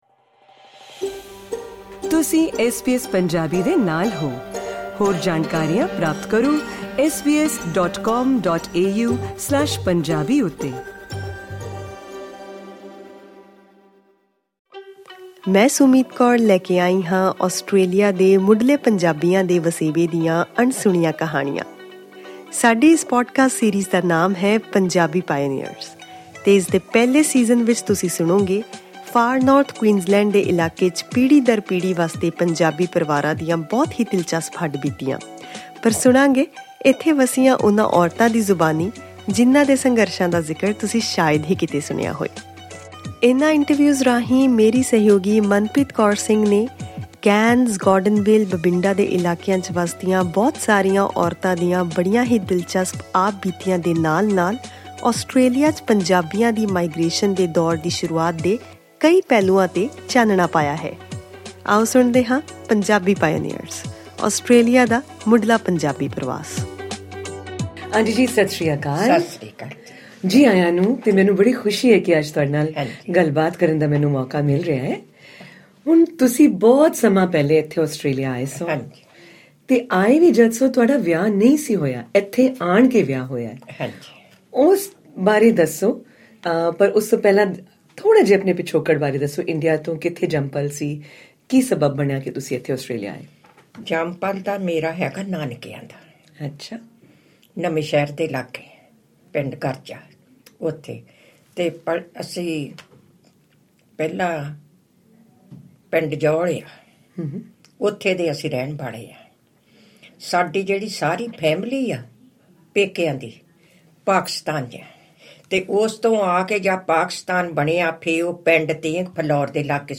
Click on the audio icon to listen to the full report and interview in Punjabi.